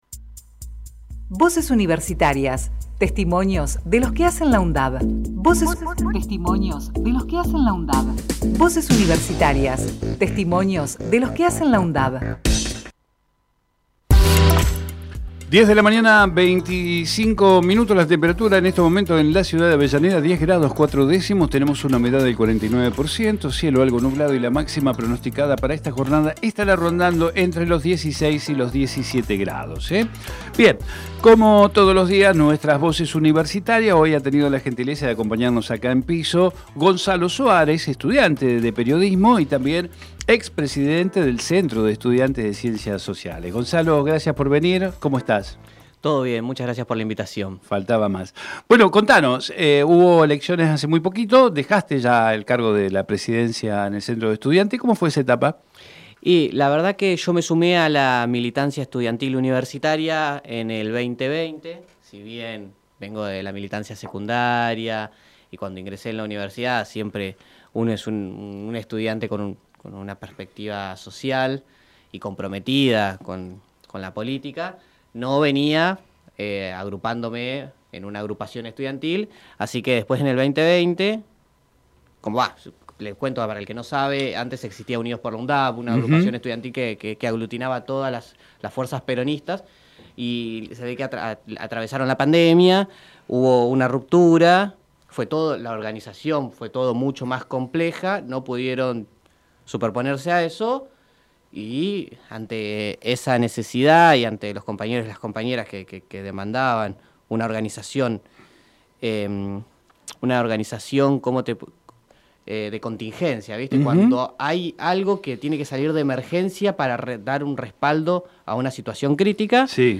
Compartimos con ustedes la entrevista realizada en Territorio Sur